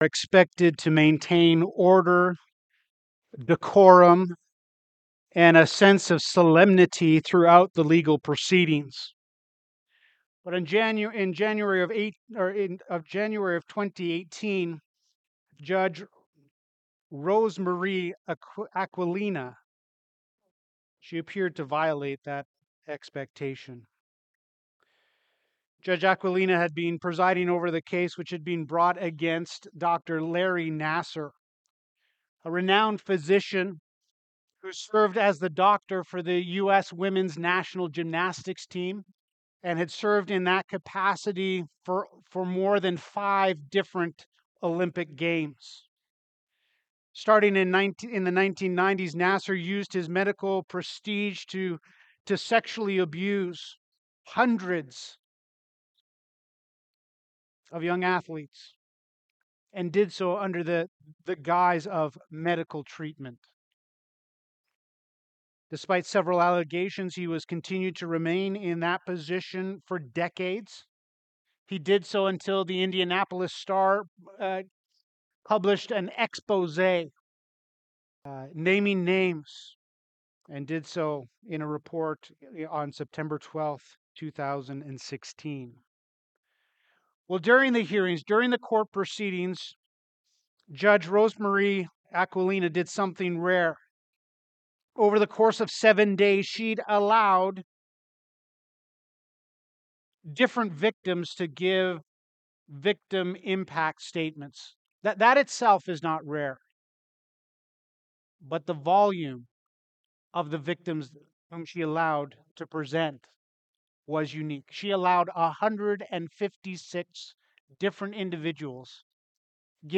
Sermons - Grace Bible Fellowship